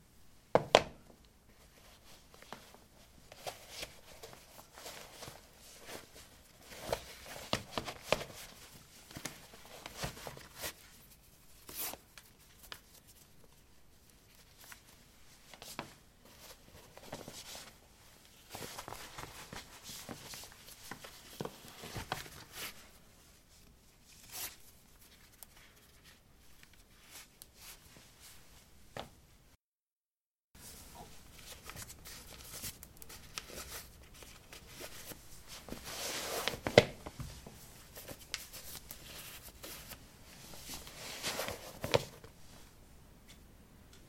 粘性步骤" Pasos Rapidos Liquido Viscoso
描述：这是我在一个大石墙工作室的嘴里的RAW录音。它是完美的粘性步骤，在某种特殊的温软地板上。我使用Zoom H1，它有一点噪音减少。
标签： 步骤 粘度 粘性 足迹
声道立体声